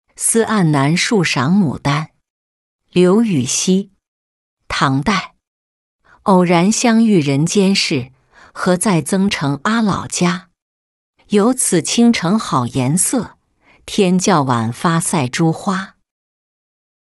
思黯南墅赏牡丹-音频朗读